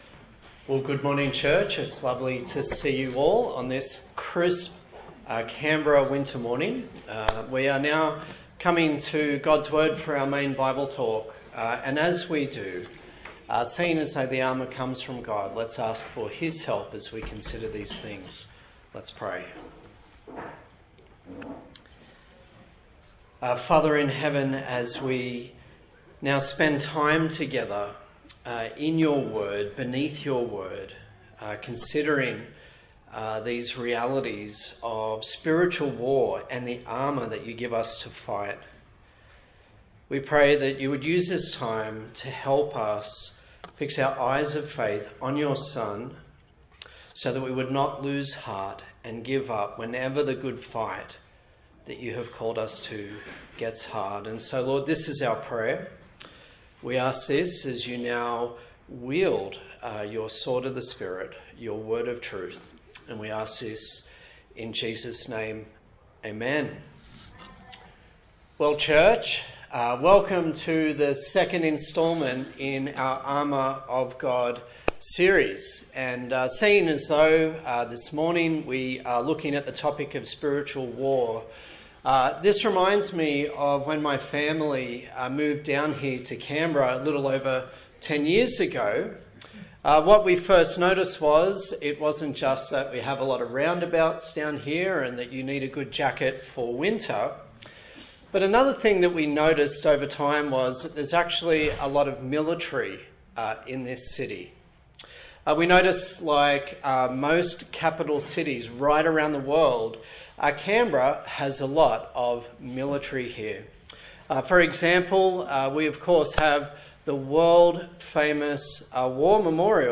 A sermon in the series on the Full Armour of God from Ephesians
Service Type: Morning Service